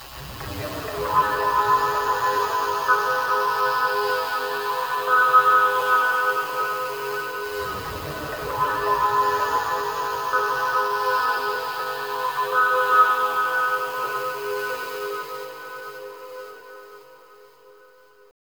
64BELLS-FX-L.wav